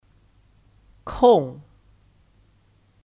空 (Kòng 空)